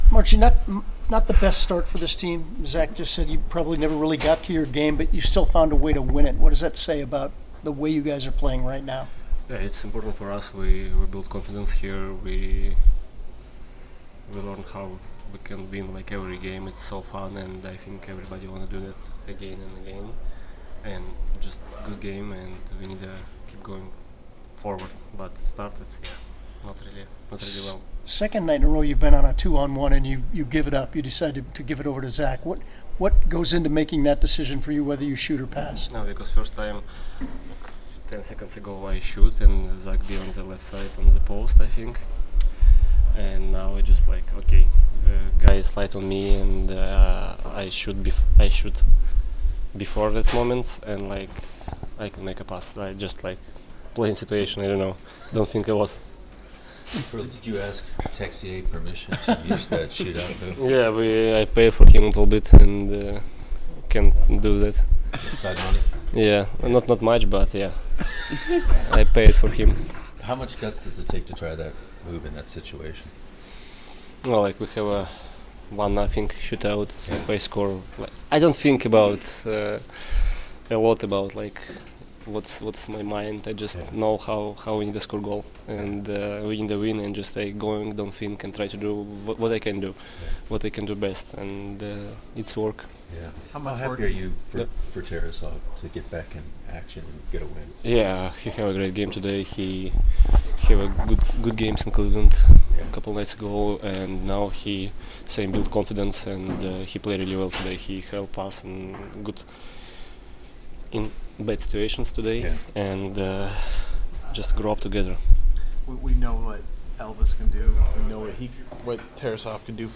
Streaking Blue Jackets beats division rival Philadelphia Flyers in Shootout, 3-2; RW Kirill Marchenko Postgame Interview